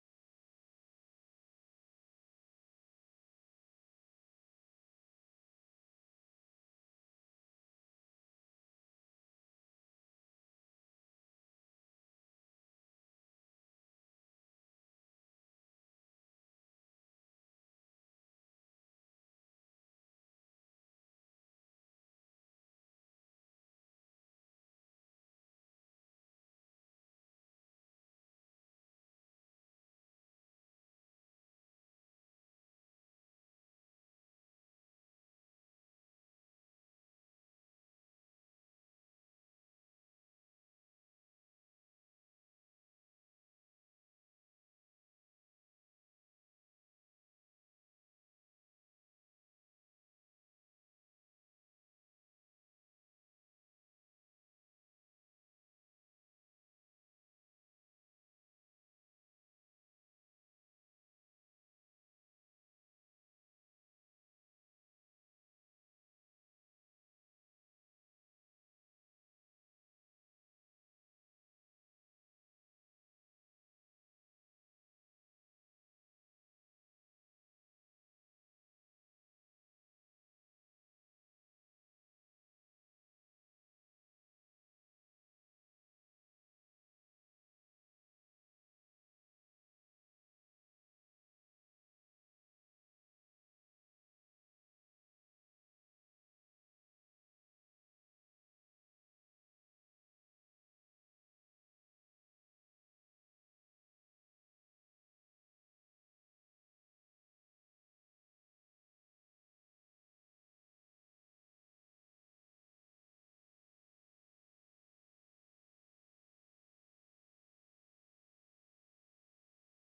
29.  (Organ/Organ)
Holst-Mars-04-Organ_1.mp3